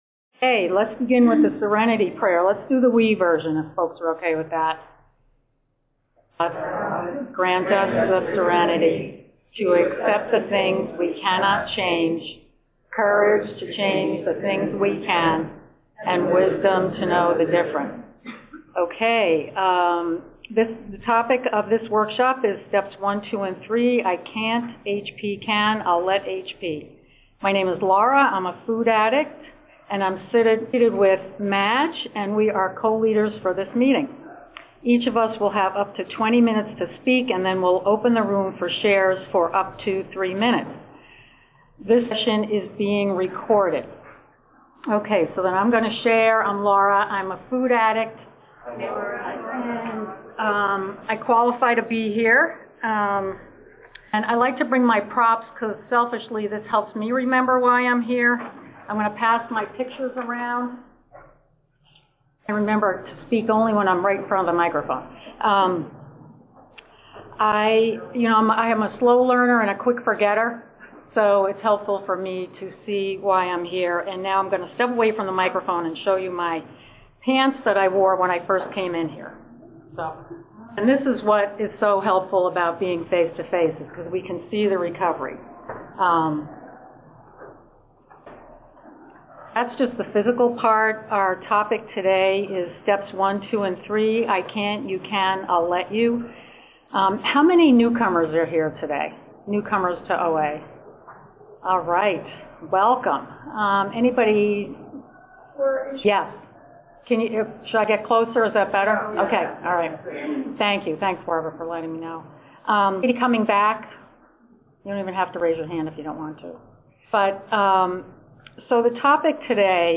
A workshop given at the 2024 OA Region 6 convention, held in October in Nashua, NH, US.